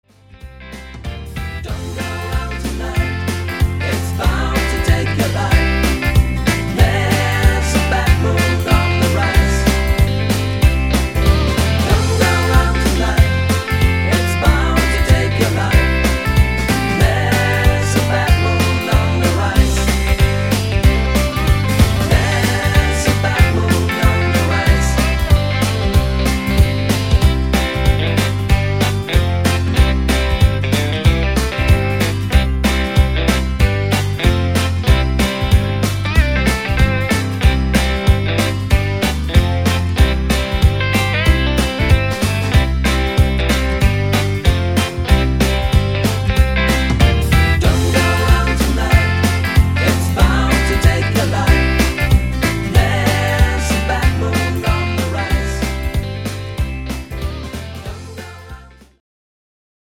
Easy To Sing - C-Dur